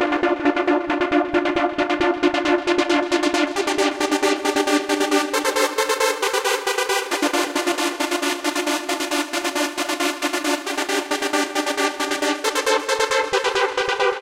It also creates rich pads and synth strings.